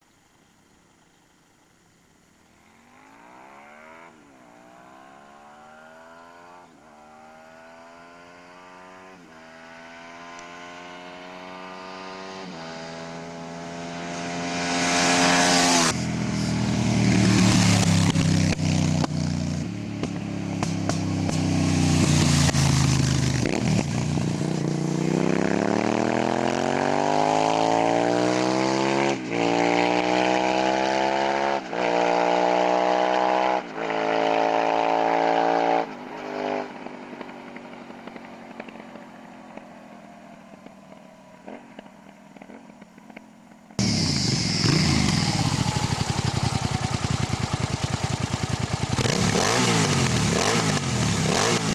Ha "durrogtatni" akarsz, vegyél Hyosung GT650-et !
GT650R hang GPR dobbal!